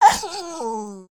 Minecraft Version Minecraft Version 1.21.5 Latest Release | Latest Snapshot 1.21.5 / assets / minecraft / sounds / mob / wolf / sad / bark3.ogg Compare With Compare With Latest Release | Latest Snapshot
bark3.ogg